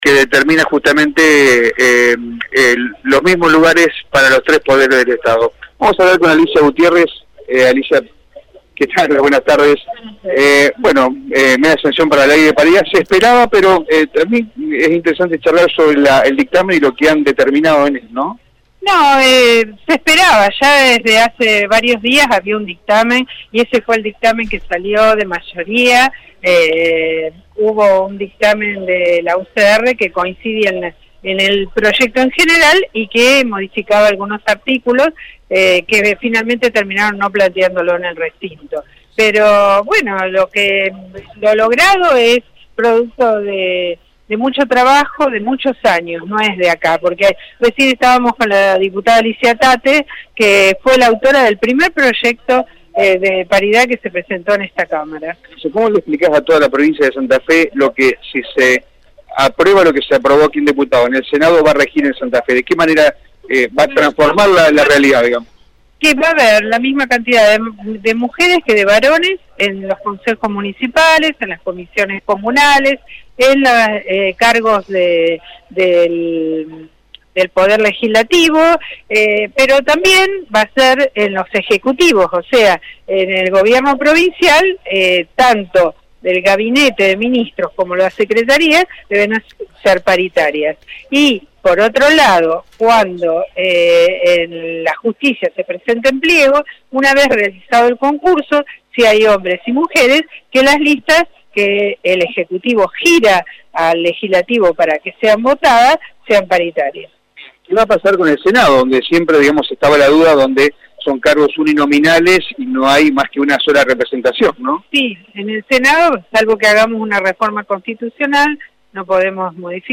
Para Radio EME, la diputada Alicia Gutiérrez indicó que «va a haber la misma cantidad de varones y mujeres en los tres poderes del Estado.»